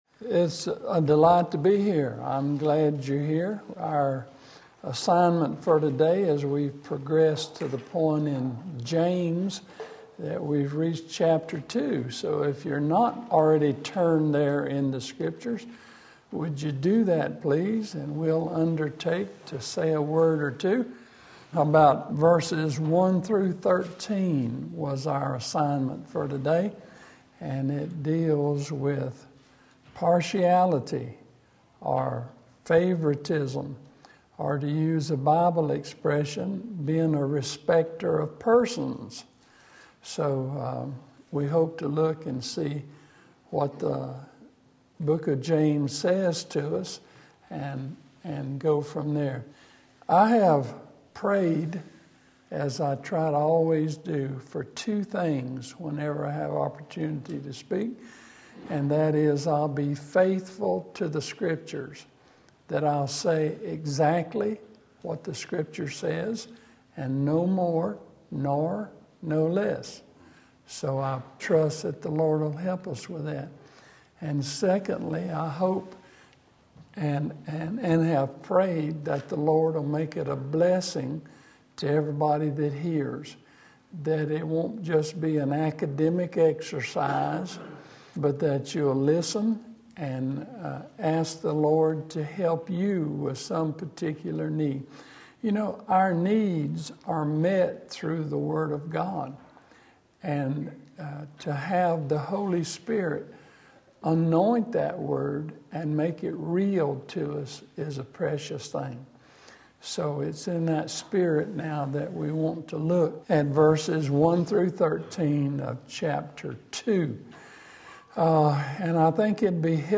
Corporately and Individually James 2:1-3 Sunday School